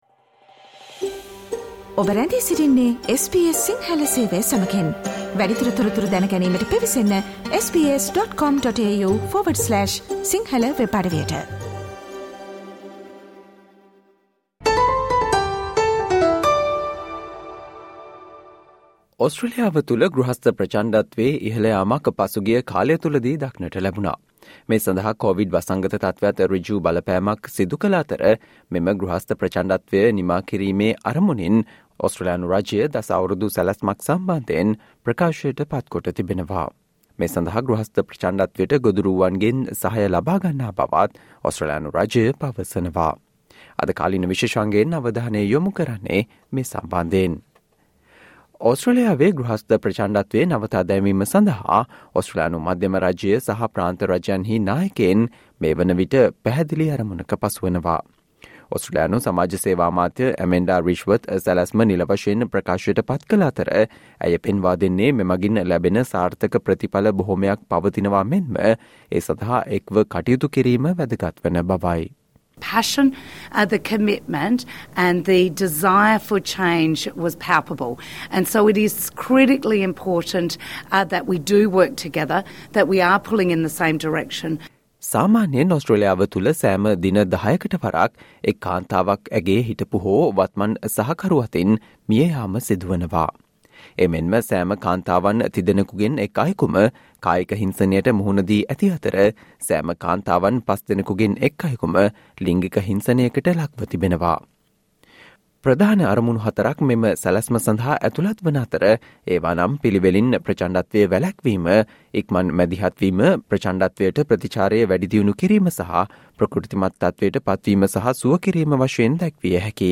Today - 18 October, SBS Sinhala Radio current Affair Feature on The ambitious plan to end violence against women and children within a generation